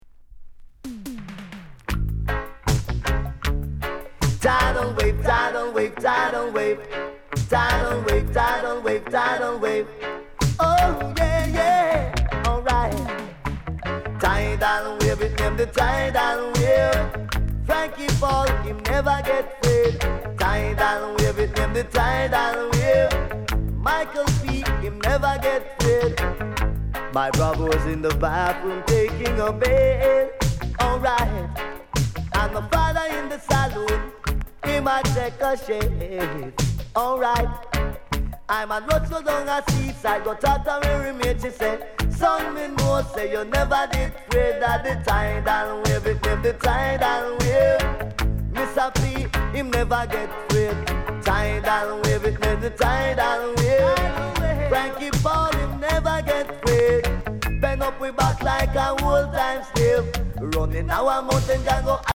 SLIGHT WARP